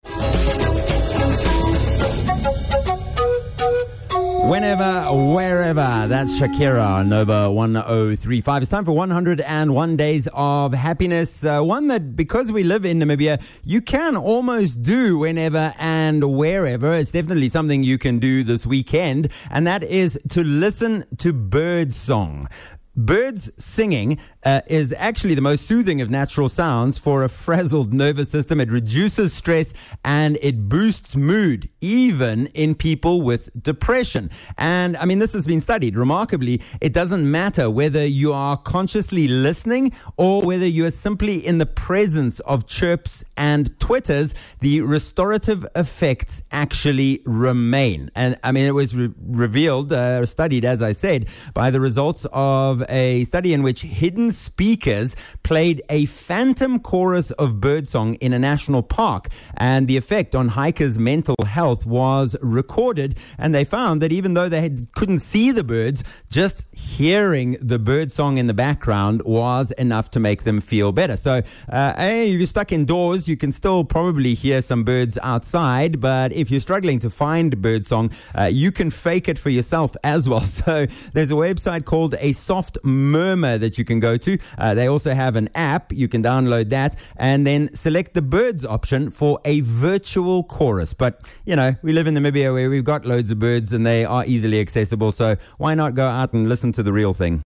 Listen to birdsong.